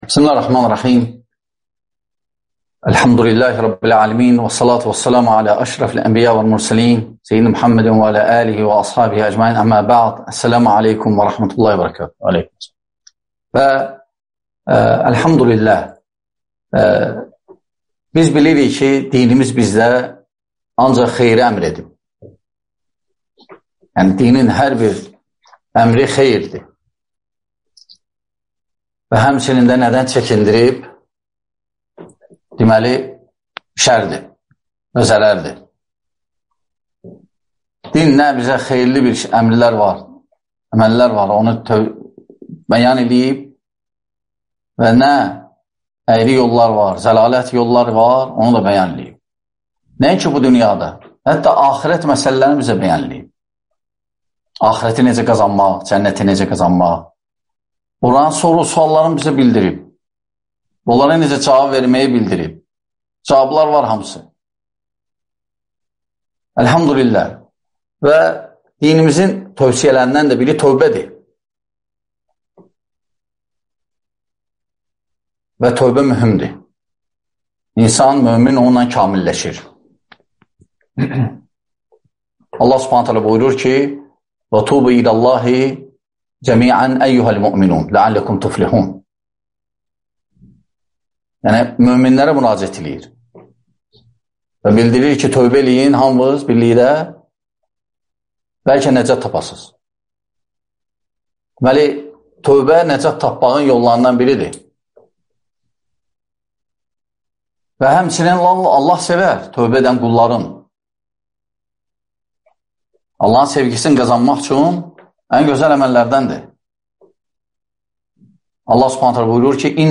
Günahlar, tövbə, kəffarə (DƏRSLƏR